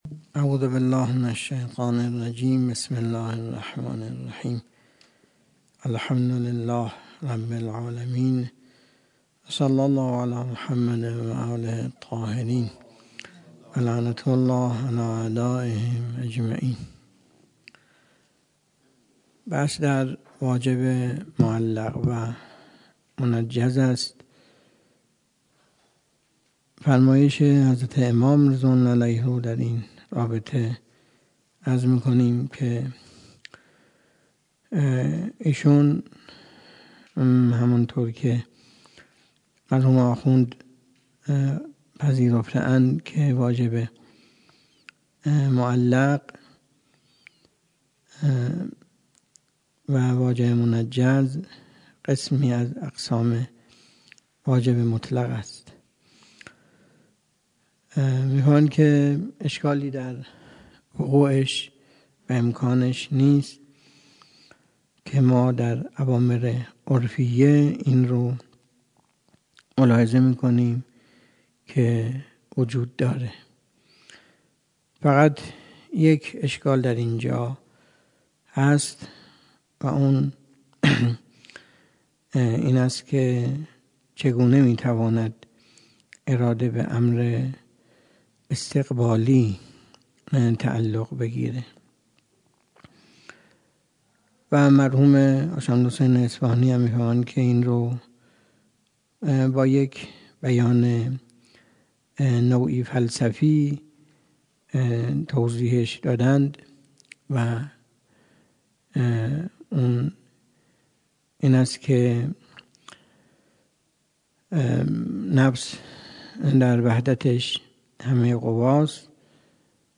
درس خارج اصول
سخنرانی